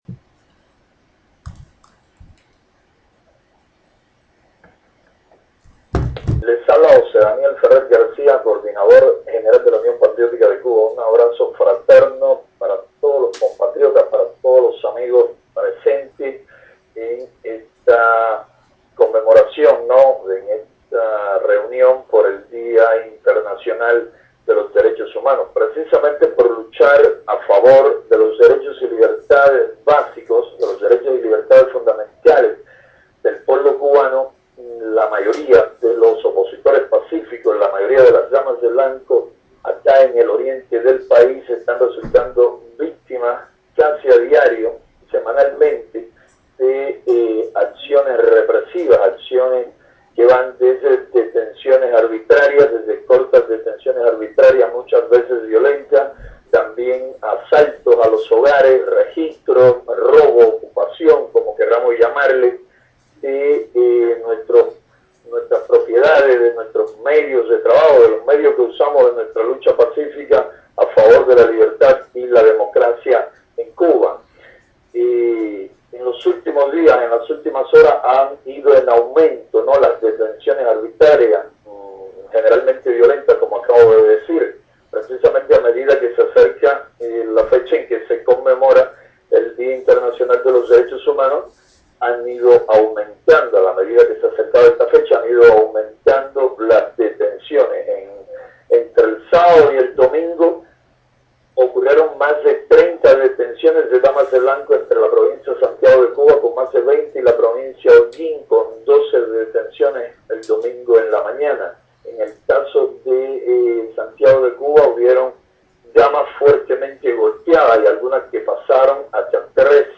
Palabras, desde Cuba, del José Daniel Ferrer García, coordinador general de la Unión Patriótica de Cuba, en la conmemoración por el Día Internacional de los DDHH organizada en Madrid por varias organizaciones disidentes cubanas (entre ellas el Observatorio CDH).